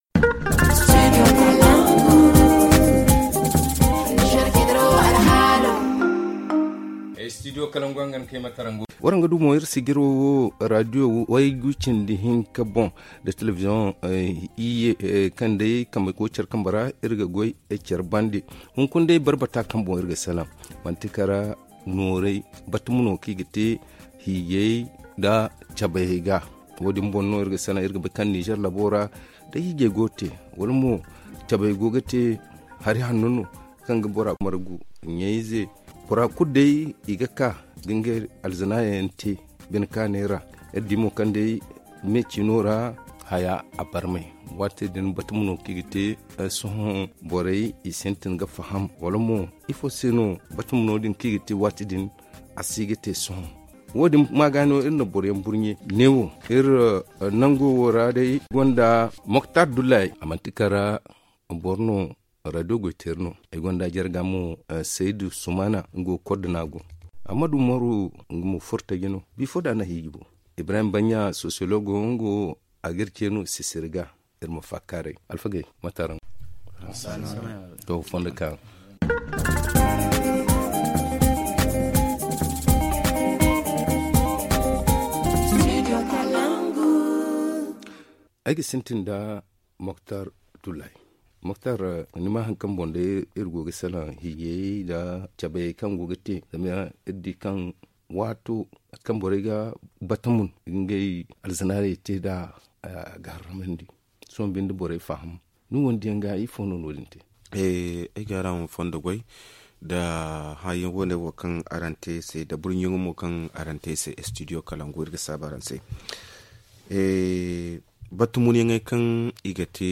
Rediffusion : La réduction des dépenses lors des cérémonies de baptême et mariages - Studio Kalangou - Au rythme du Niger